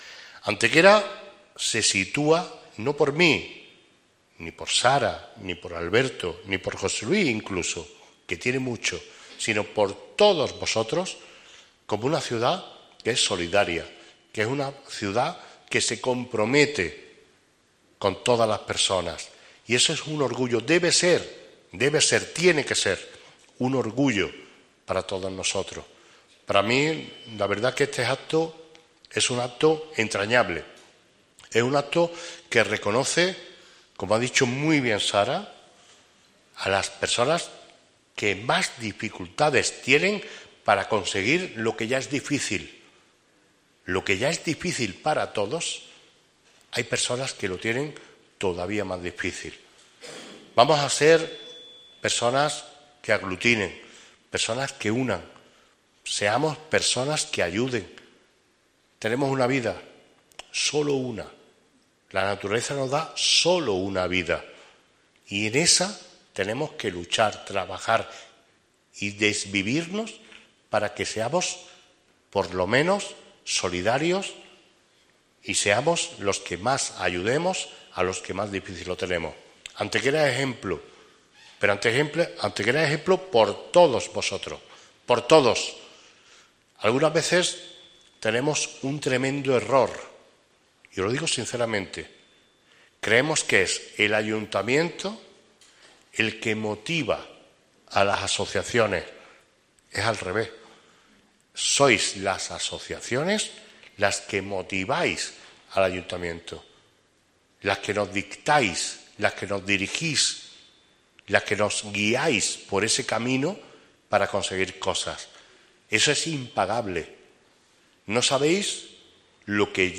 El Salón de Plenos del Ayuntamiento de Antequera acoge el acto de entrega del X Premio a la Solidaridad
Cortes de voz